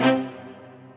stringsfx2_3.ogg